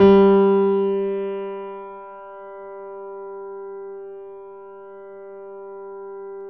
Index of /90_sSampleCDs/USB Soundscan vol.17 - Keyboards Acoustic [AKAI] 1CD/Partition B/02-GRANDP MO